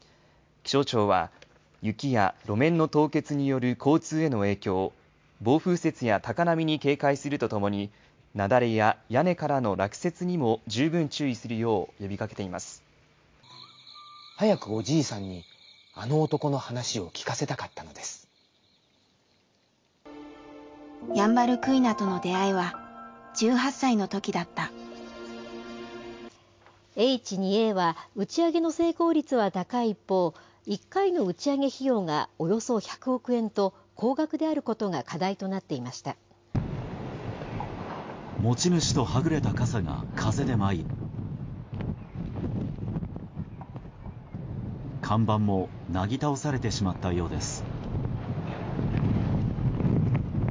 今回のデモでは、ReazonSpeechコーパスのサンプル音声として公開されている5本の短い音声を用い、これらをFFmpegで1本に連結しました。
1. 気象庁による天気情報(ニュース音声)
2. 童話風の短文(読み上げ、背景に虫の声)
3. ヤンバルクイナとの出会いに関する文章(ナレーション、音楽付き)
4. H2Aロケットの打ち上げ情報(ニュース音声)
5. 風で舞った傘と看板の描写(ニュース音声、背景に風の音)
5人の話者が明瞭に区切られた音声だということもありますが、話者分離の精度を示す指標 Diarization Error Rate（DER） は 0% となります。
今回のデモ音声には、虫の声や風の音、音楽などの環境音も含まれています。